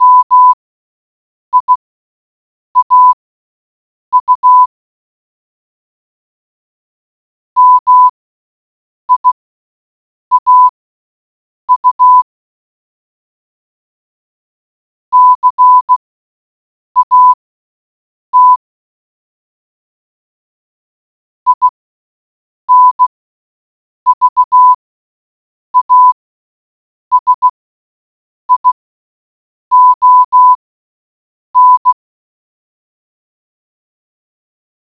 Al escucharlo, podemos identificar que es un código morse, así que si tenemos experiencia en código morse, podemos traducirlo; o utilizar una herramienta que nos traduzca los pulsos.